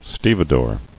[ste·ve·dore]